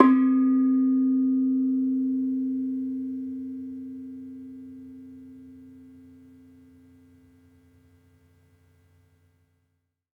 Bonang-C3-f.wav